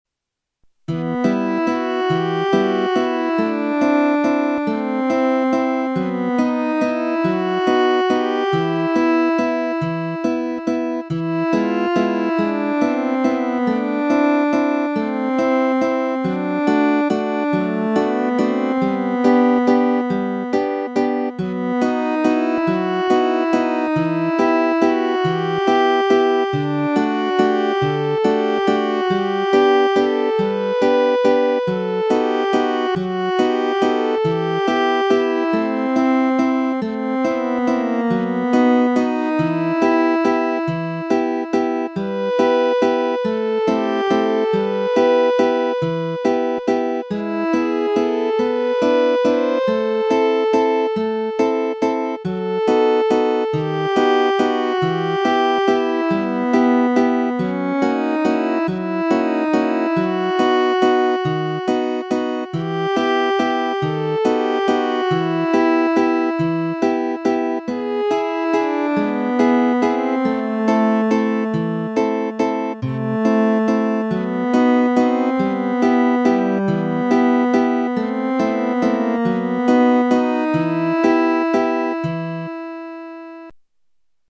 Valssi.
Waltz 1997